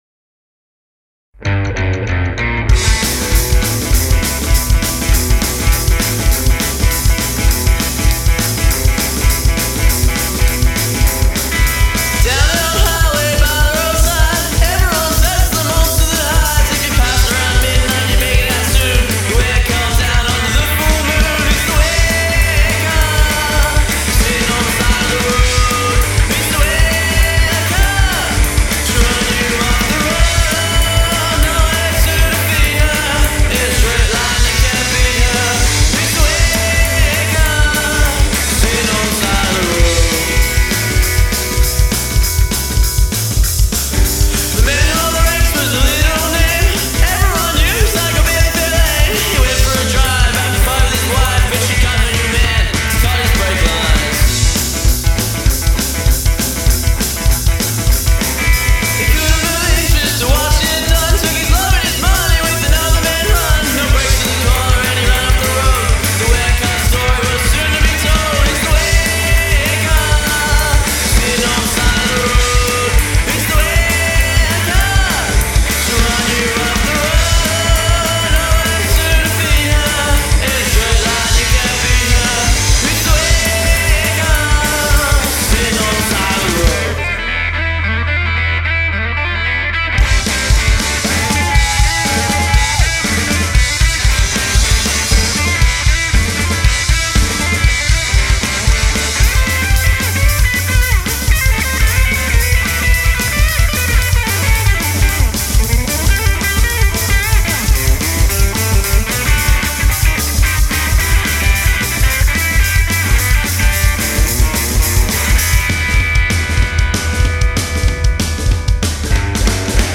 often giving the songs a haunting feel. 5 track debut EP.